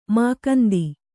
♪ mākandi